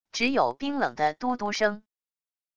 只有冰冷的嘟嘟声wav音频